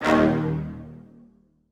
TS Brass Hit.wav